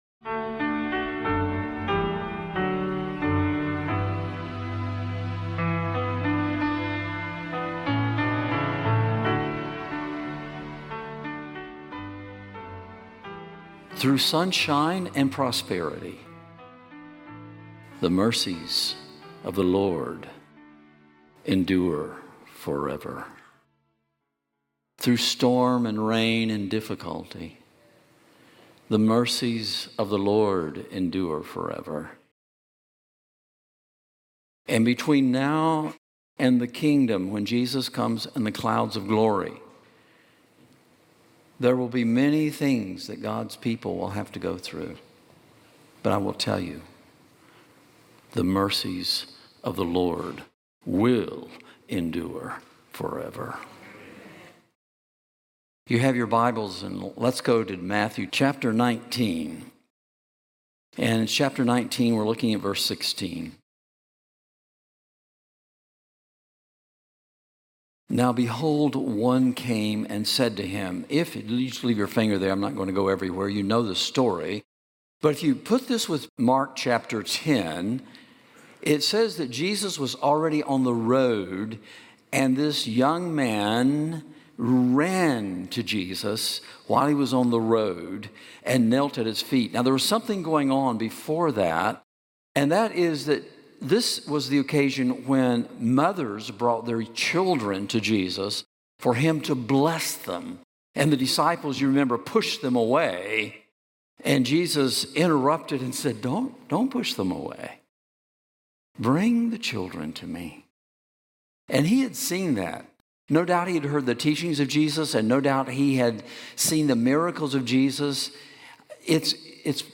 This sermon dives deep into the heart of discipleship, showing that true fulfillment comes not from rule-keeping or wealth, but from surrendering fully to Christ.